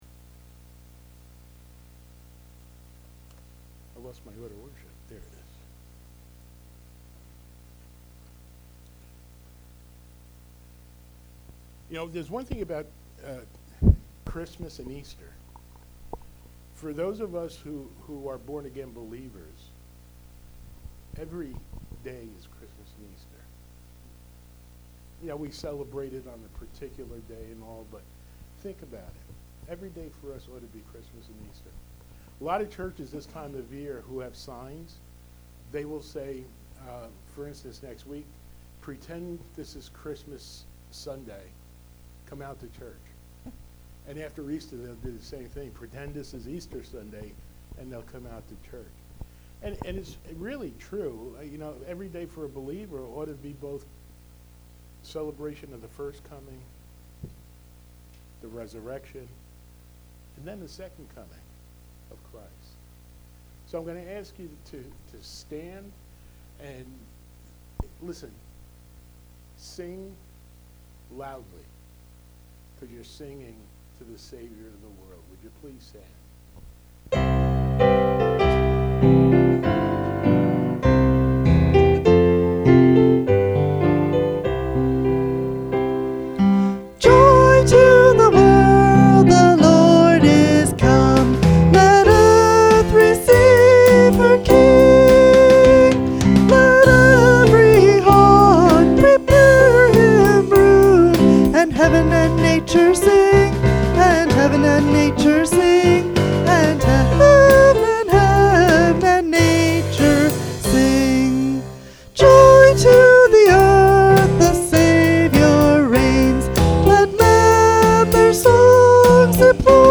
Series: Sunday Morning Worship Service